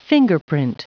Prononciation du mot fingerprint en anglais (fichier audio)
Prononciation du mot : fingerprint